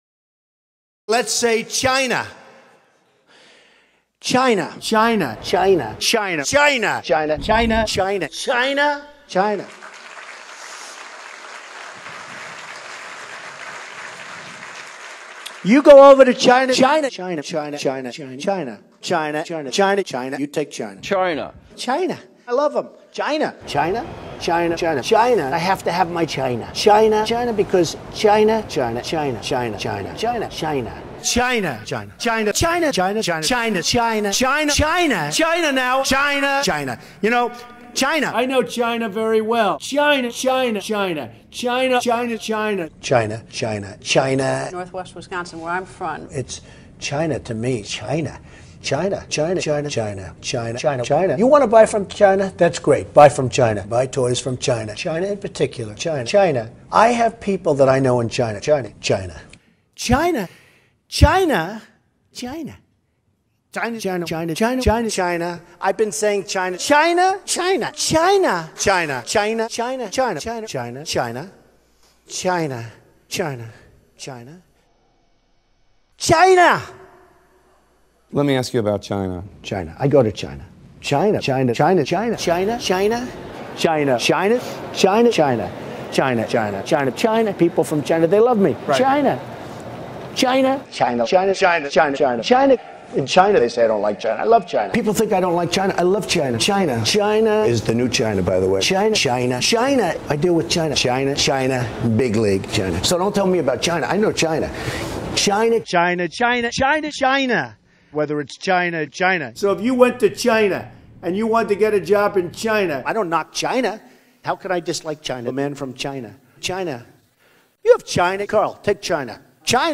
Donald Trump Says 'China'.f140.m4a